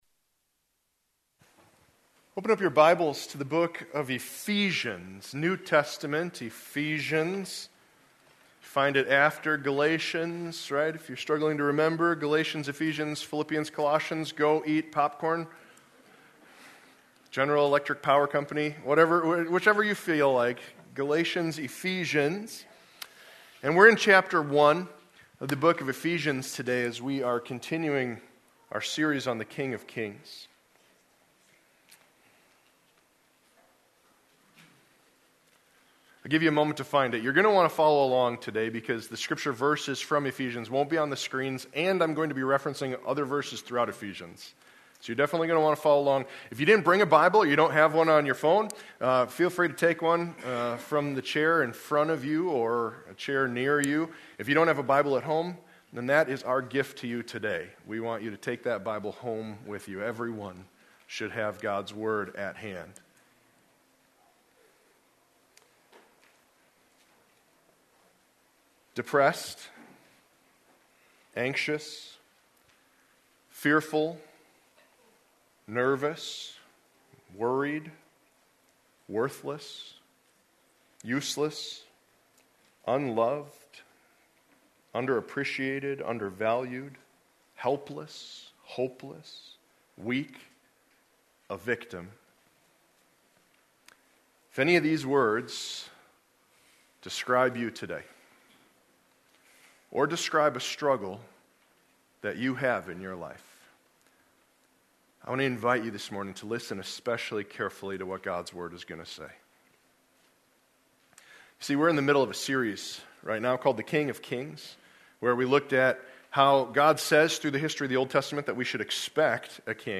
Sunday Sermons | Monroe Bible Church